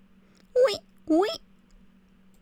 WEEDLE.wav